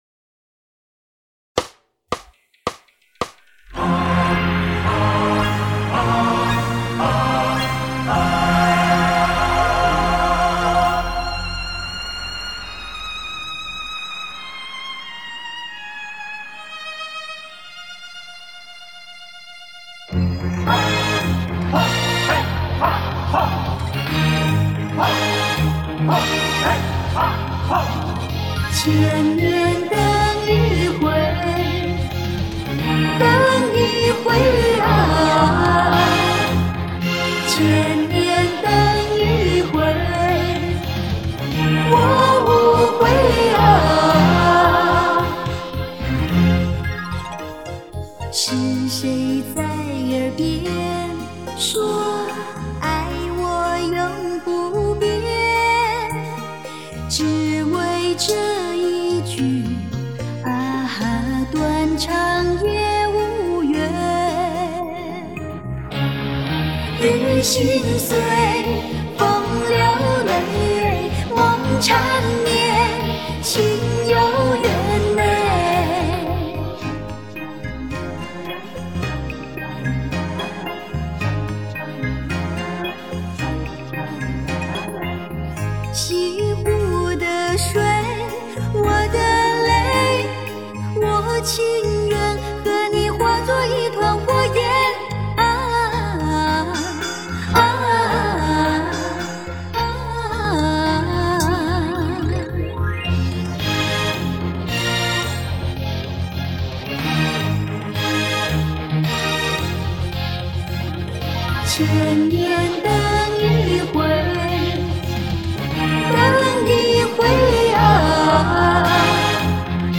每分钟110拍